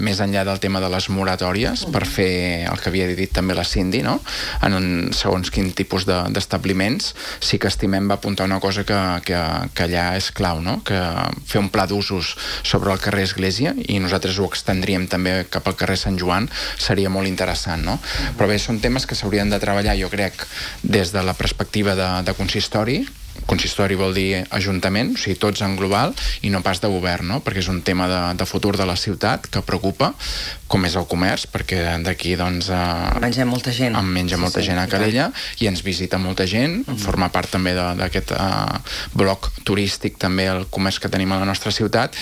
Xavier Ponsdomènech, regidor i portaveu d’ERC, que aquest dilluns ha passat per l’espai de l’entrevista, ha defensat la creació d’un pla d’usos per regular els tipus d’establiments al centre.